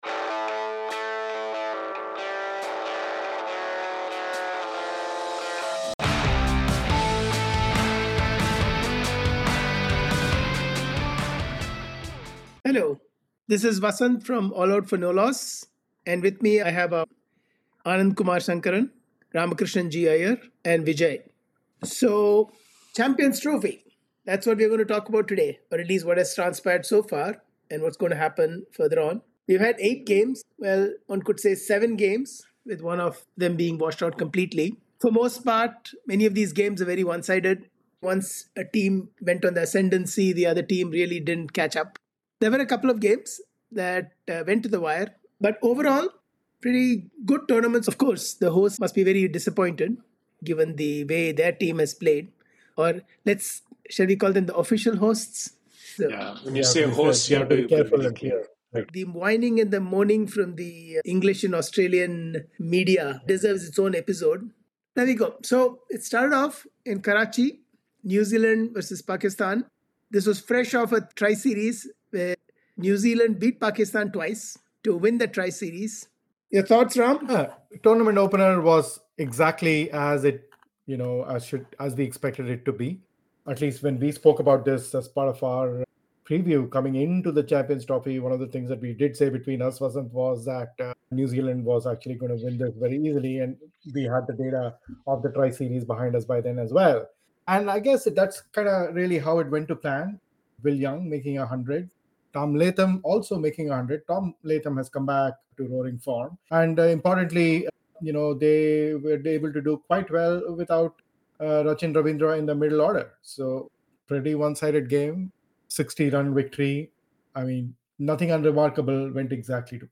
Australia, South Africa and Afghanistan will be battling for the two semi-final slots from group B. In this conversation we will take a look at the 8 games that have been played and discuss what we liked and did not from each time.